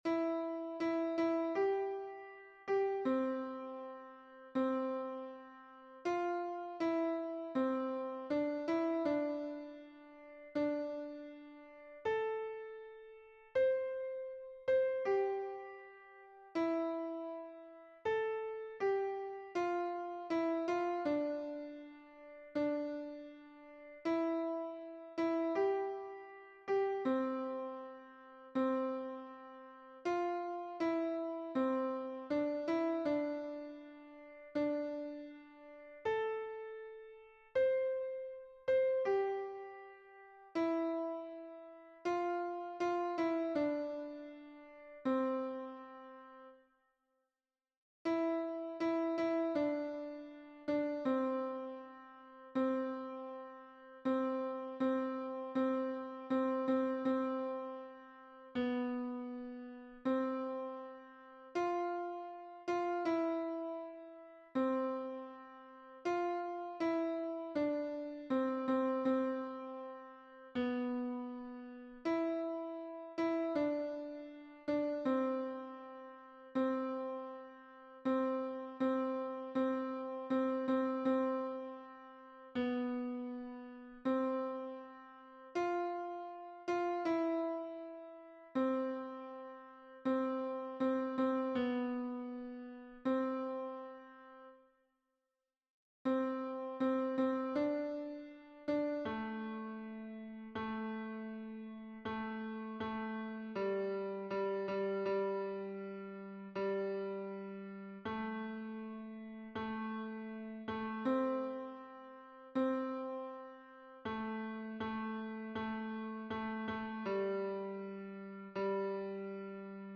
Voci tracce S.A.T.B.(mp3)